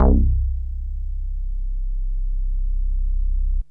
SYNTH BASS-1 0020.wav